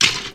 skeleton_step.mp3